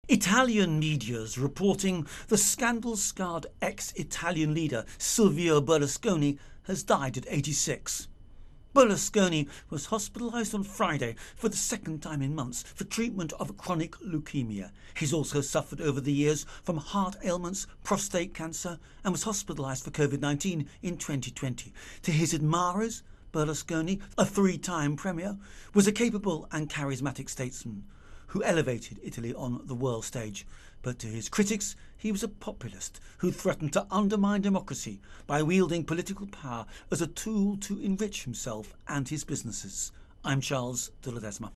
reports on Italy Obit Berlusconi.